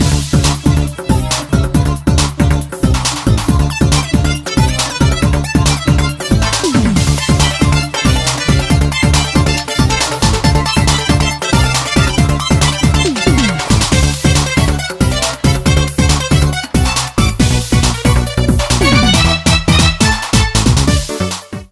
Loops to the start of the song with fadeout